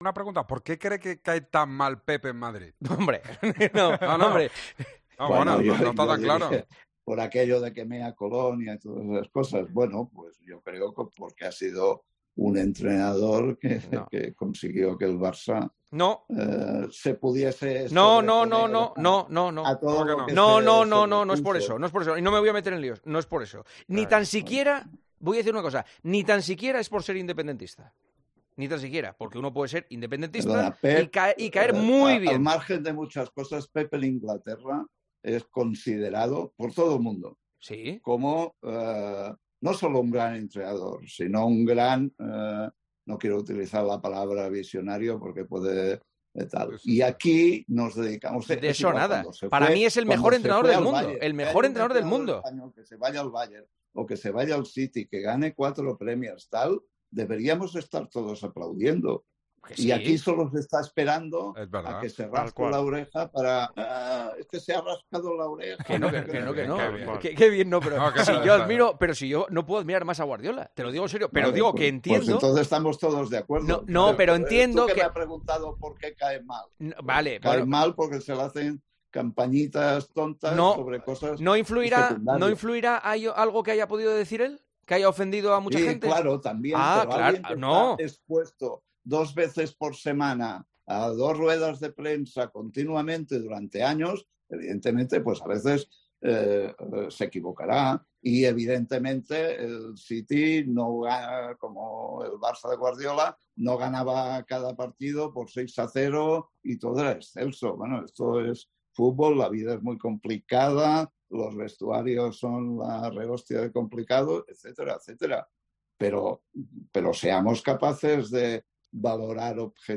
Jaume Roures pasó por los micrófonos de El Partidazo de COPE y se abrió en un momento de la entrevista este melón en el que el presentador dio su punto de vista